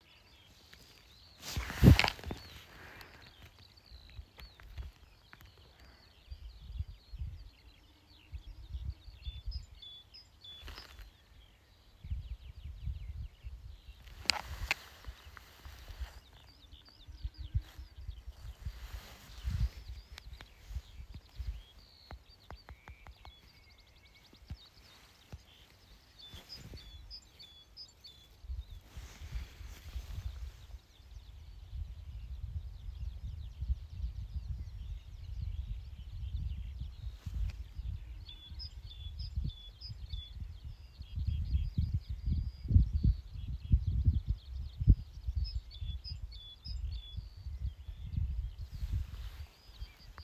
Birds -> Waders ->
Common Snipe, Gallinago gallinago
StatusVoice, calls heard
NotesNāk saucieni no meža puses